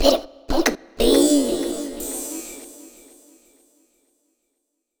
tag fx.wav